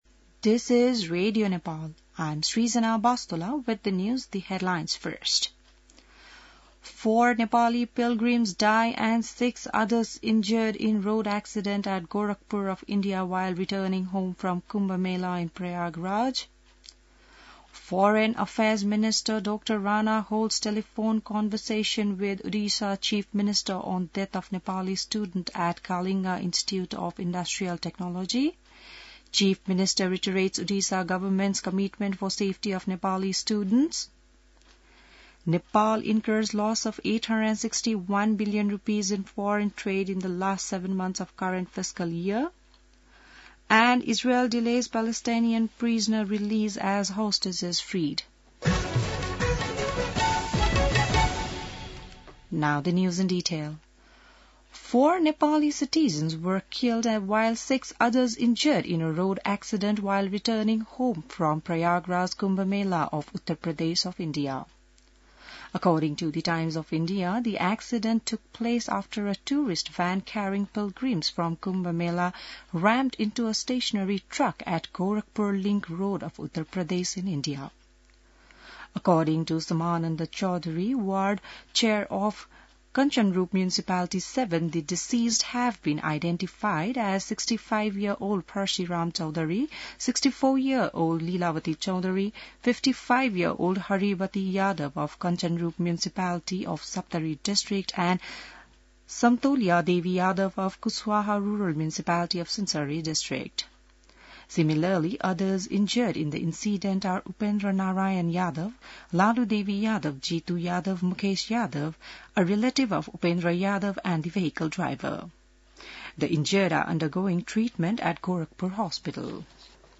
An online outlet of Nepal's national radio broadcaster
बिहान ८ बजेको अङ्ग्रेजी समाचार : १२ फागुन , २०८१